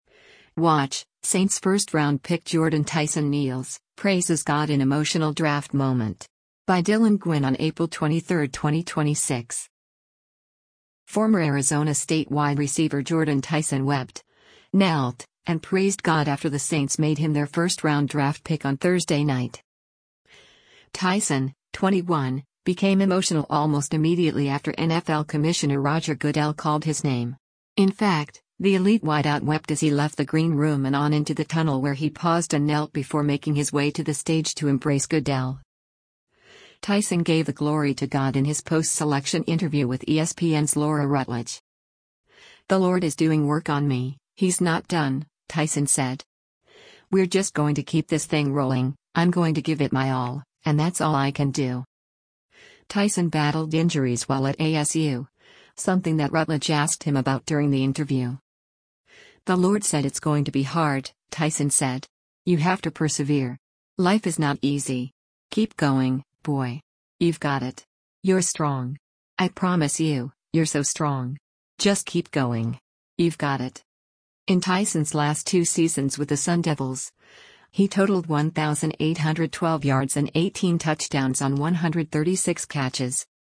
Tyson gave the glory to God in his post-selection interview with ESPN’s Laura Rutledge.